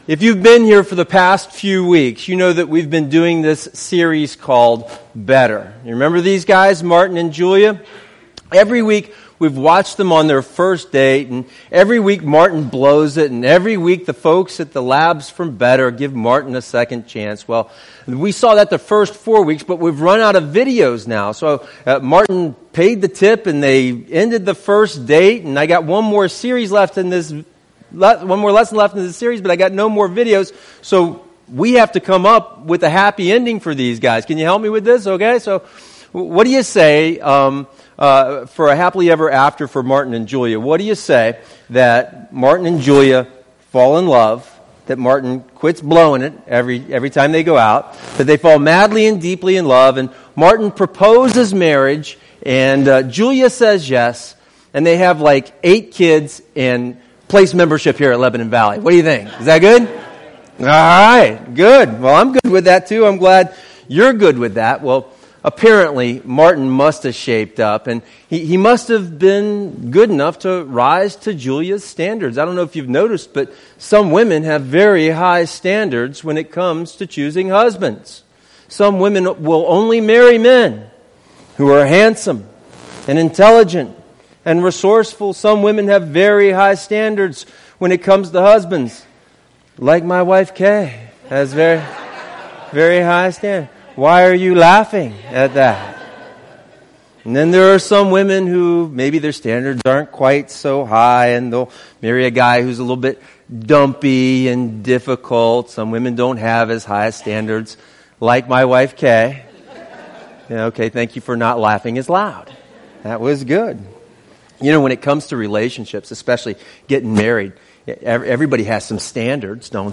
The final lesson in a series on discipleship covering how anyone is welcome.